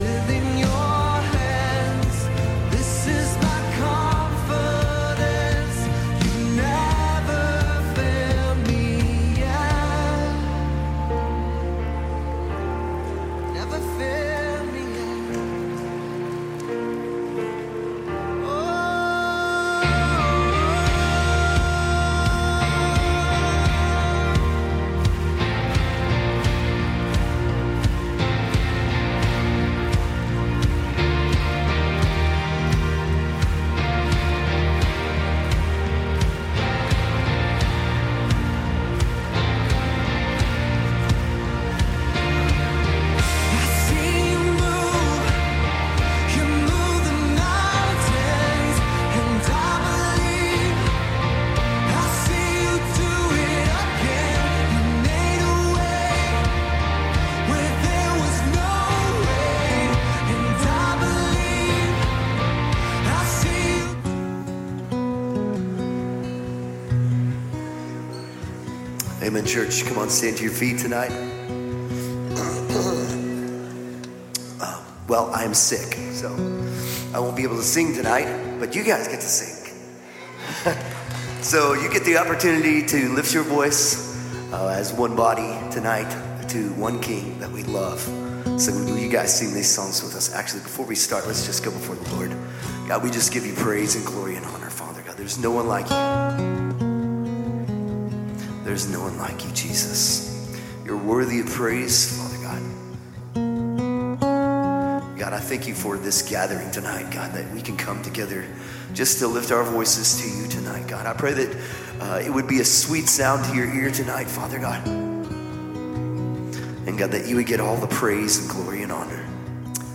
Calvary Knoxville Midweek Live!